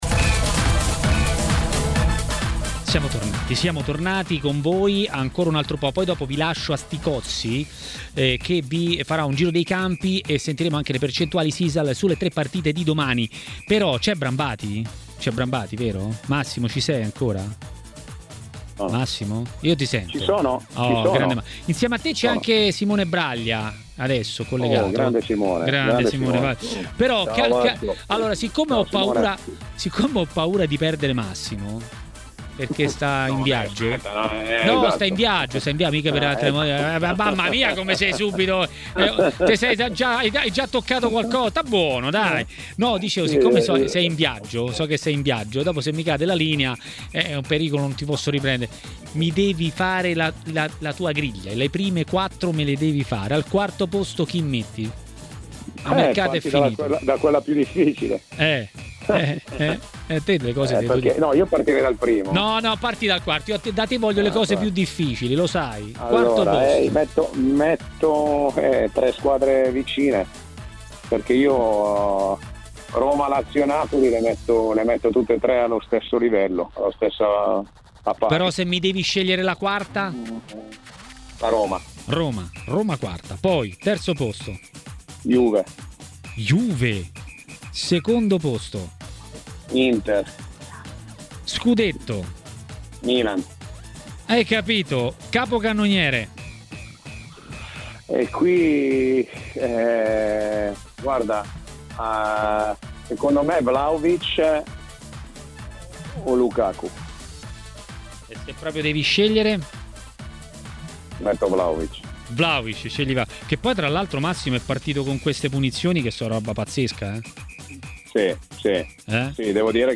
A intervenire a Maracanà, nel pomeriggio di TMW Radio, è stato l'ex calciatore Francesco Colonnese.